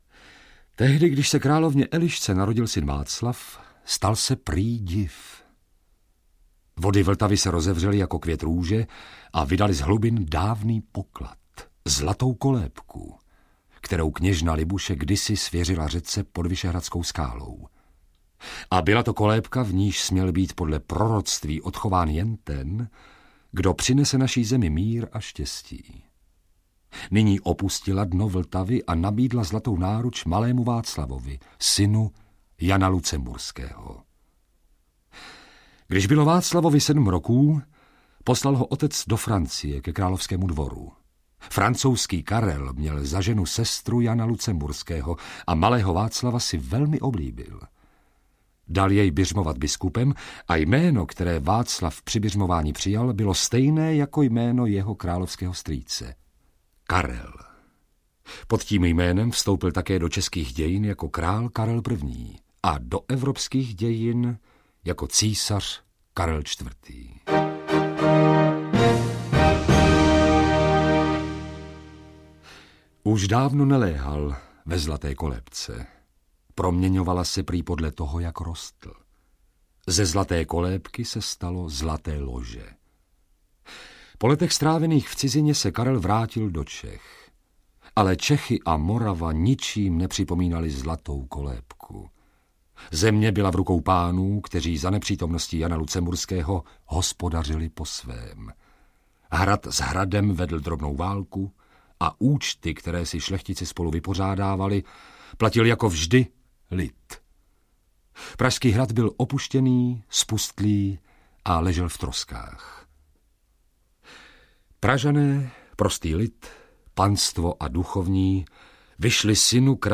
Královská Praha - Praha v pověstech, mýtech a legendách audiokniha
Ukázka z knihy
• InterpretJosef Somr, Boris Rösner, Hana Maciuchová, Jana Hlaváčová, Jiří Klem, Otakar Brousek st.